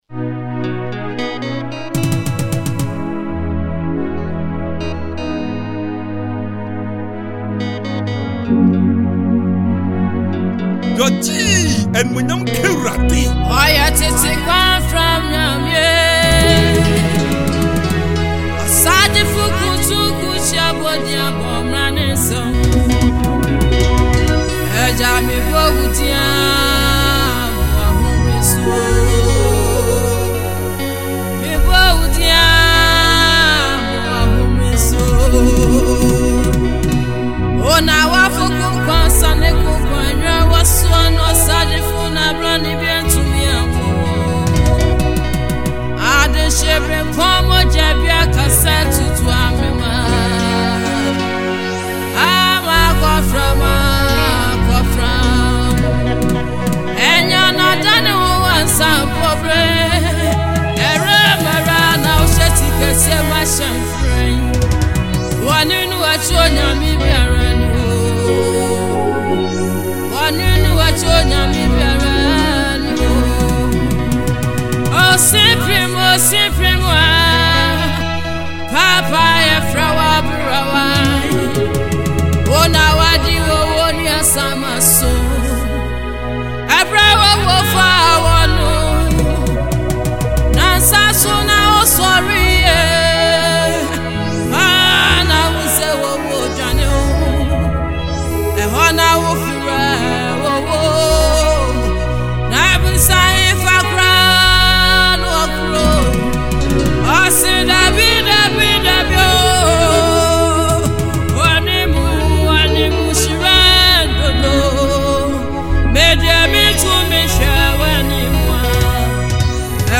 Gospel
This soulful track
powerful vocals and emotional delivery
With its catchy melody and heartfelt lyrics
soulful sounds